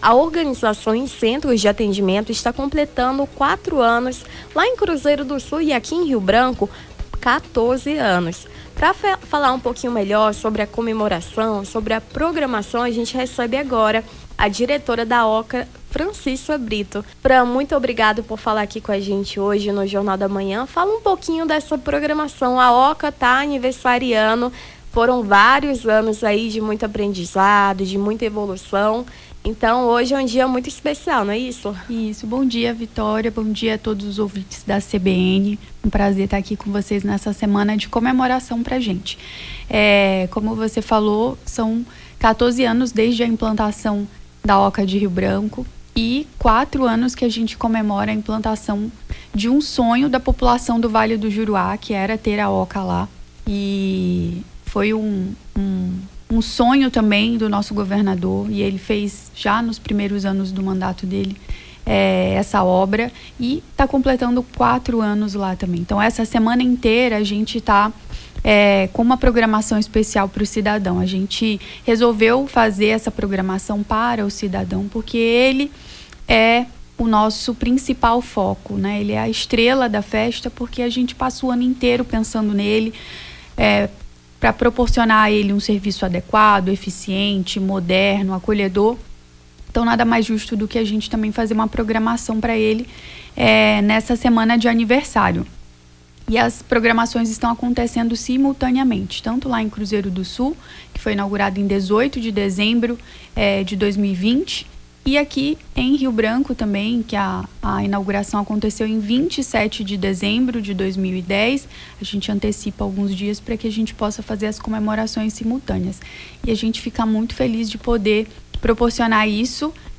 Nome do Artista - CENSURA - ENTREVISTA ANIVERSÁRIO OCA (19-12-24).mp3